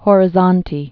(hôrĭ-zôntē, ôrĭ-zôɴchĭ)